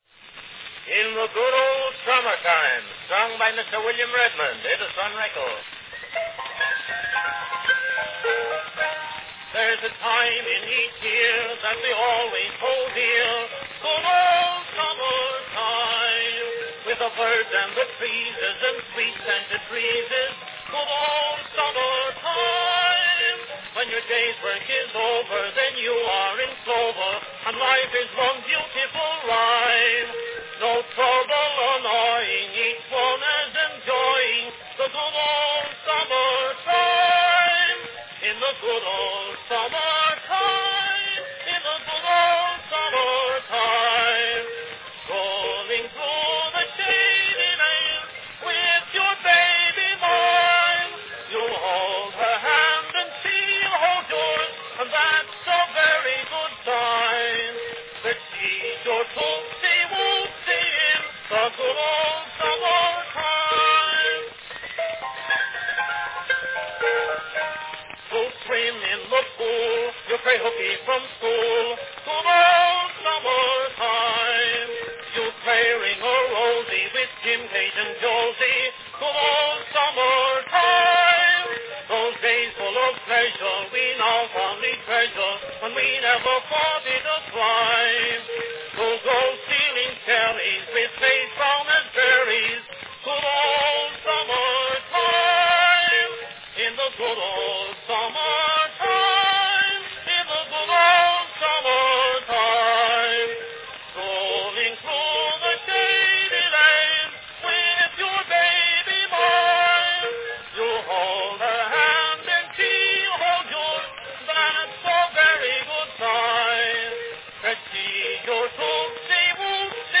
A rare early recording
Cylinder # 8118 (5" diameter)
Category Song